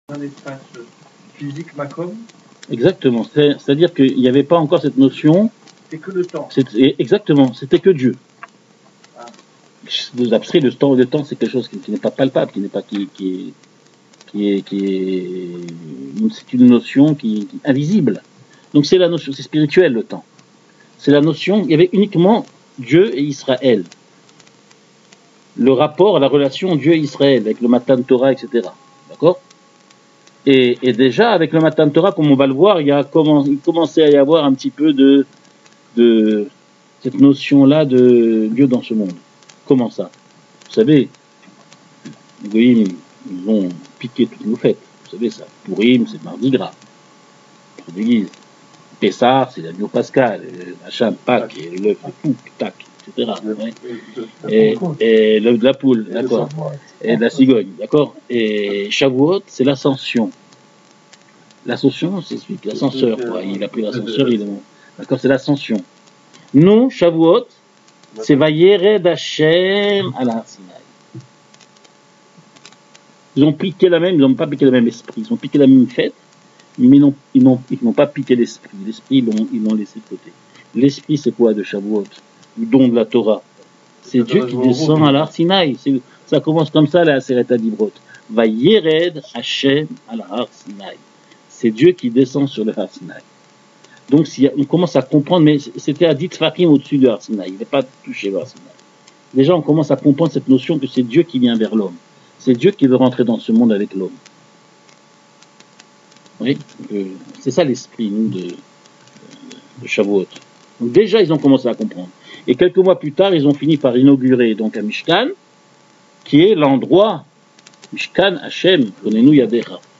Le cours est donné tous les jeudis soir.